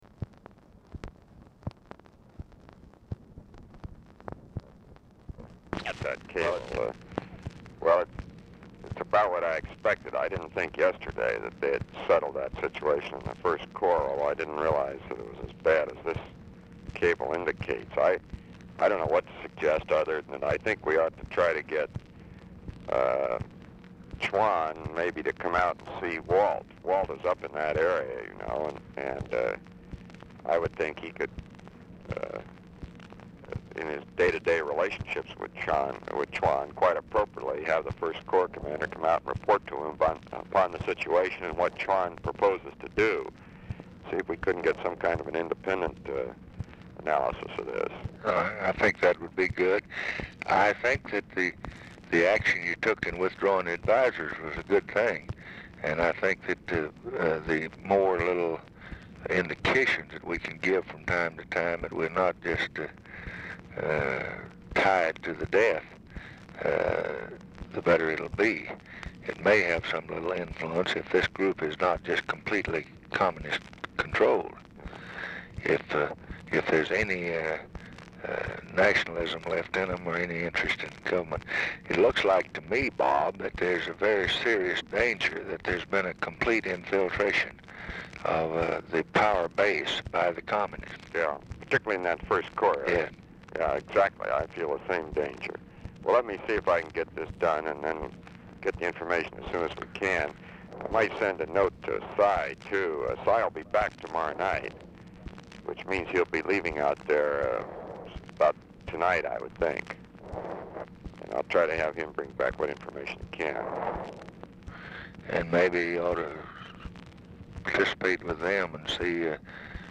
Telephone conversation # 10021, sound recording, LBJ and ROBERT MCNAMARA, 4/7/1966, 7:45AM | Discover LBJ
RECORDING STARTS AFTER CONVERSATION HAS BEGUN
Format Dictation belt
Location Of Speaker 1 Mansion, White House, Washington, DC